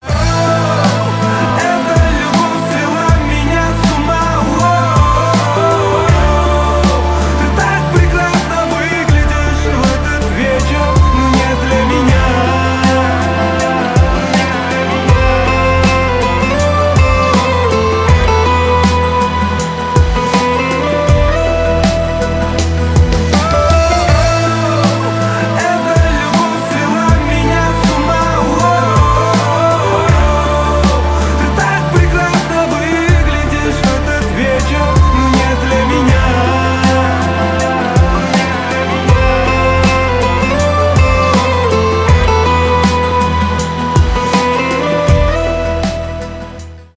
поп , красивый мужской голос